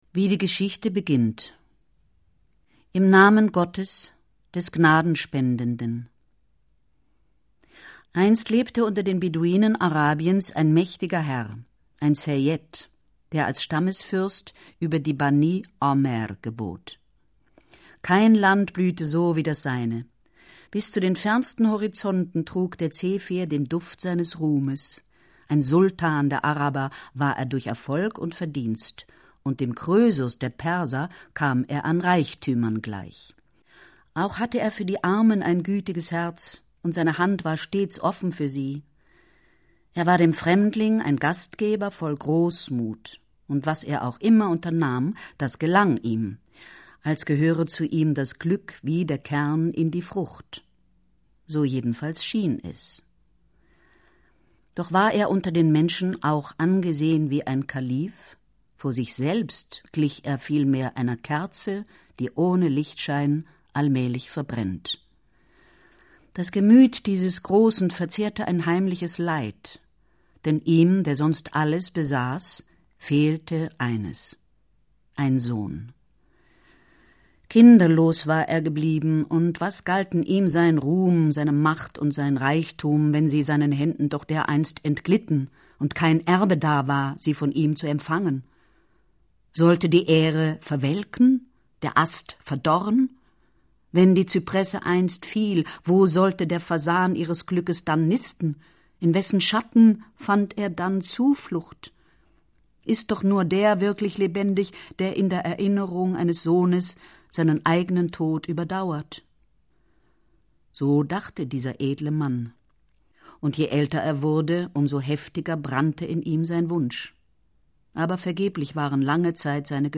Jutta Lampe (Sprecher)
Jutta Lampe ist eine deutsche Schauspielerin.